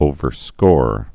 (ōvər-skôr)